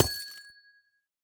Amethyst_break1.ogg.mp3